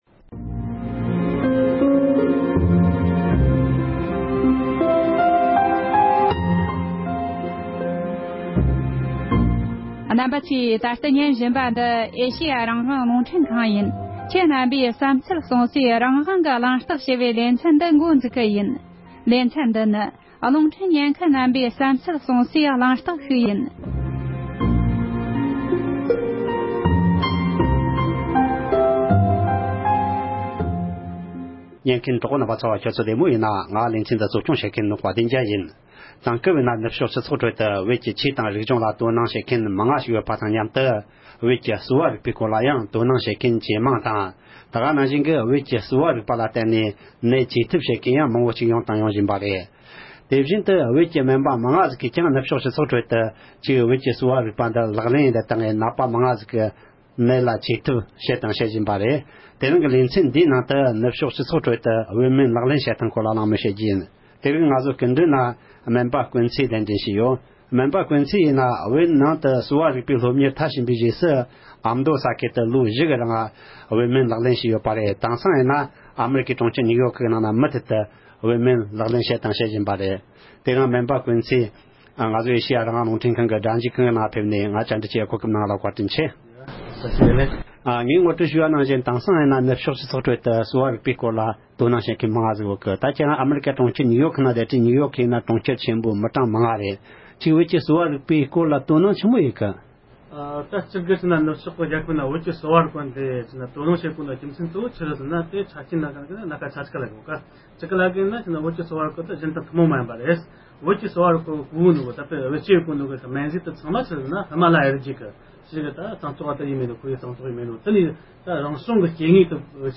ནུབ་ཕྱོགས་སྤྱི་ཚོགས་ཁྲོད་བོད་ཀྱི་གསོ་བ་རིག་པ་ལག་ལེན་བྱེད་སྟངས་སྐོར་གླེང་མོལ།